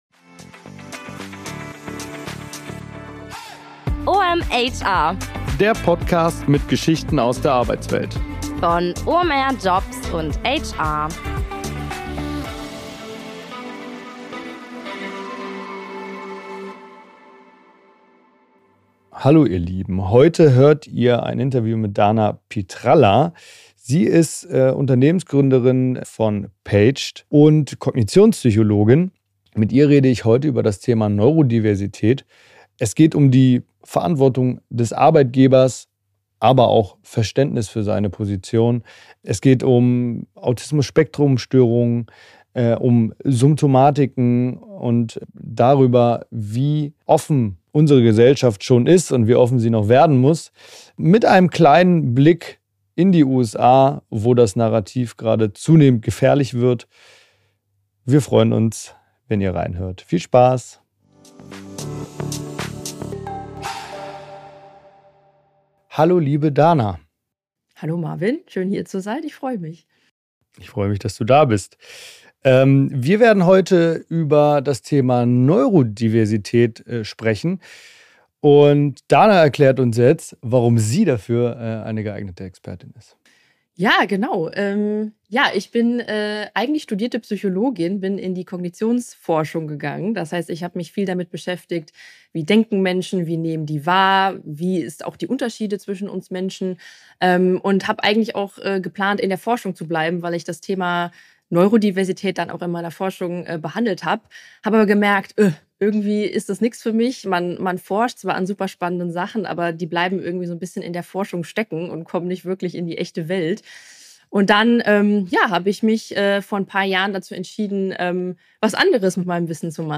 Ein Gespräch über Normen, Leistungsideale und darüber, wie Arbeit für alle funktionieren kann.